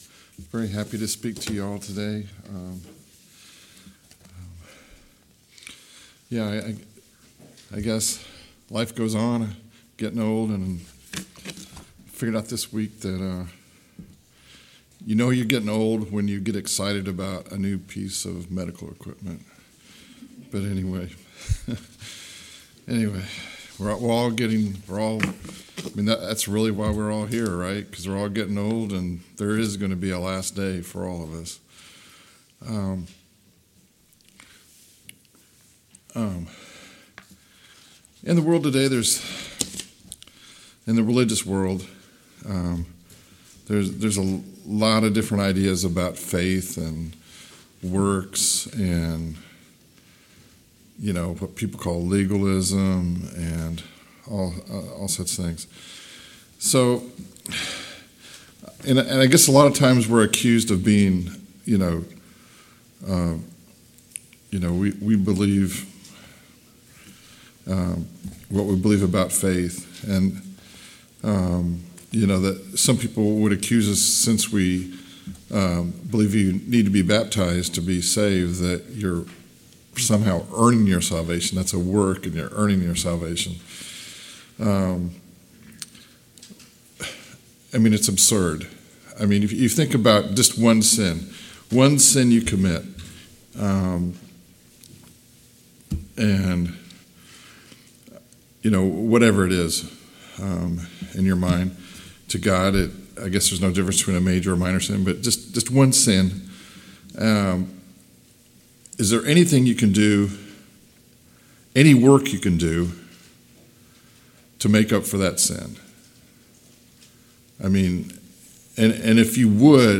Hebrew 11:6 Service Type: AM Worship « God promises to make all things work together for good 8.